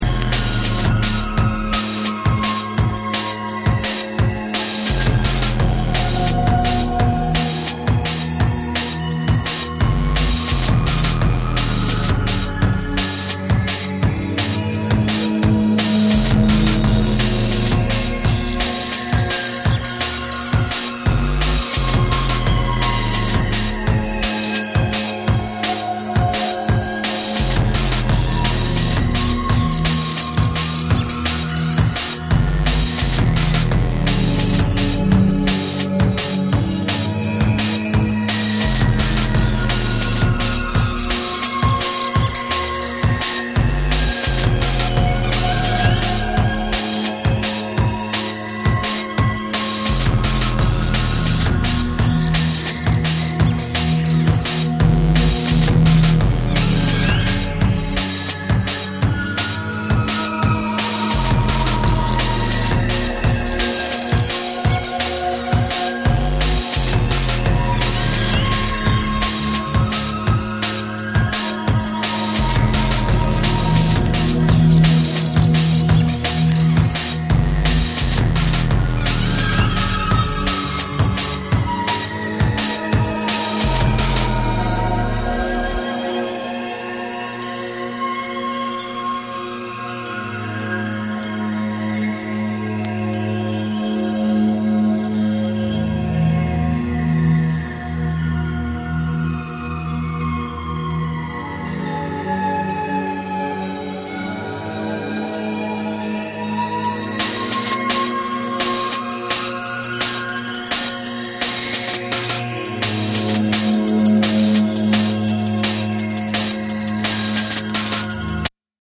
JUNGLE & DRUM'N BASS